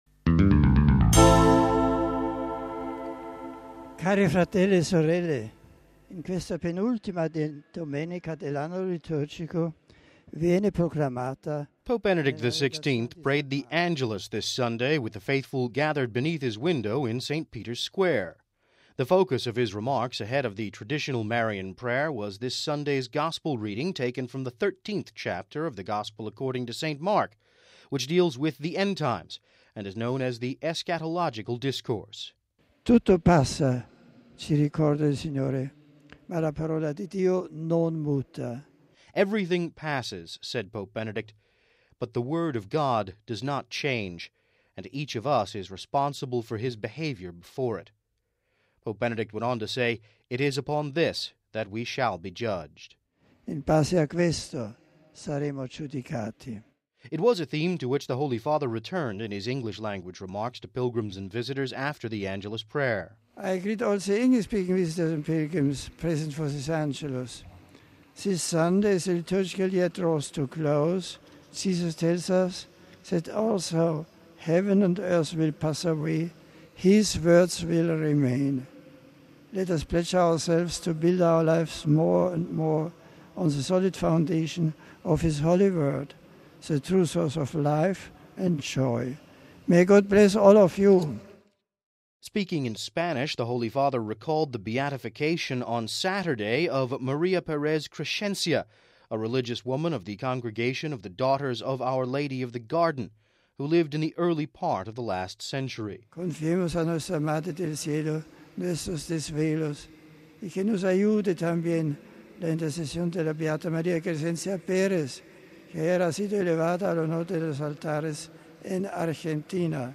(Vatican Radio) Pope Benedict XVI prayed the Angelus this Sunday with the faithful gathered beneath his window in St. Peter’s Square. The focus of his remarks ahead of the traditional Marian prayer was this Sunday’s Gospel reading, taken from the 13th chapter of the Gospel according to St. Mark, which deals with the end times and is known as the eschatological discourse. Listen to our report: RealAudio